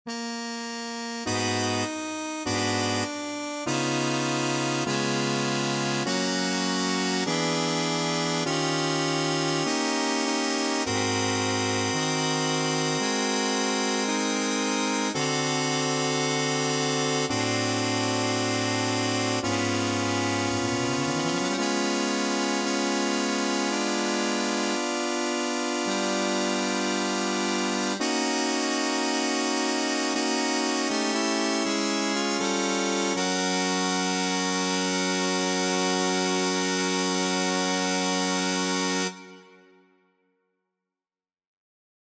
Key written in: G♭ Major
How many parts: 4
Type: Barbershop
All Parts mix: